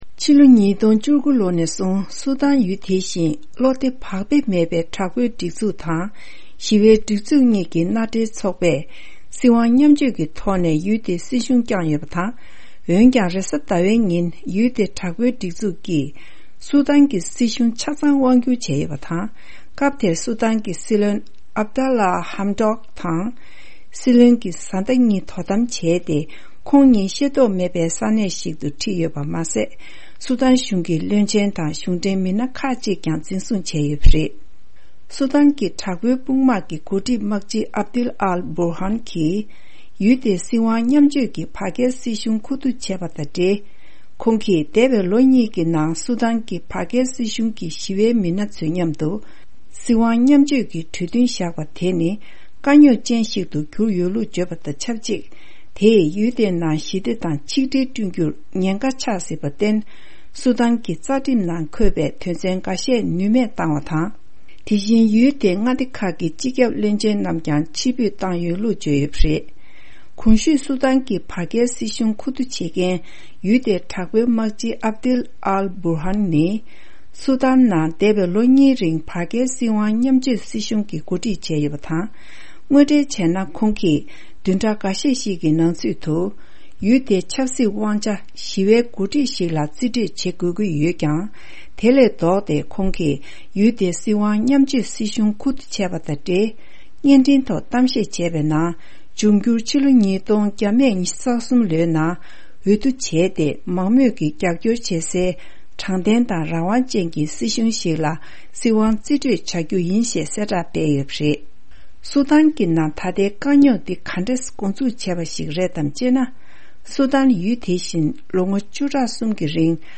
ཕབ་བསྒྱུར་དང་སྙན་སྒྲོན་ཞུས་གནང་གི་རེད།